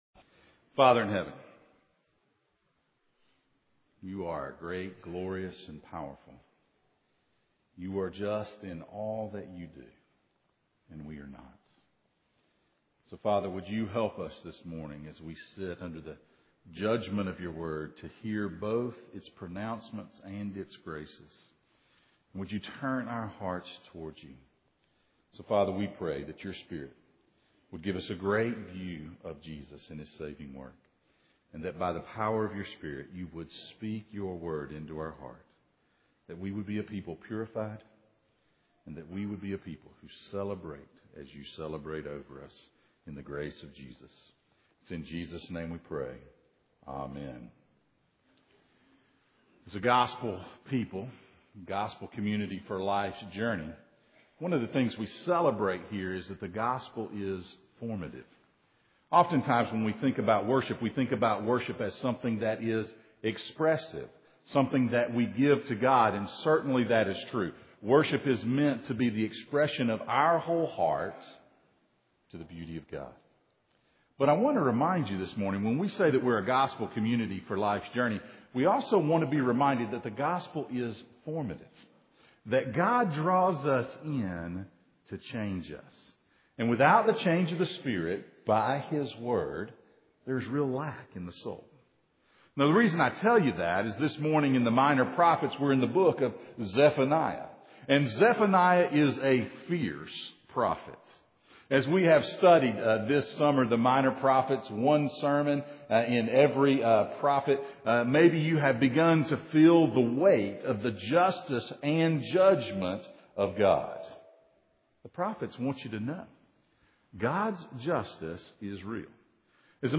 A Major In The Minors Passage: Zephaniah 1:14-2:3, Zephaniah 3:14-17 Service Type: Sunday Morning